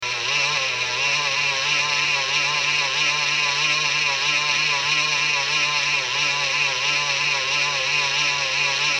Now, I found myself awaken this morning at 0651 to a grinding sound from across the room - the laptop fan is failing again!